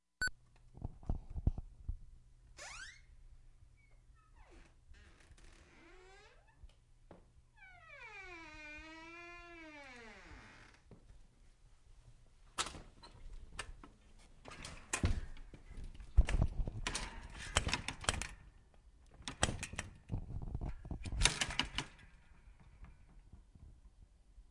门 " 门吱嘎声 1
描述：一个吱吱作响的树门打开了。
标签： 吱吱
声道立体声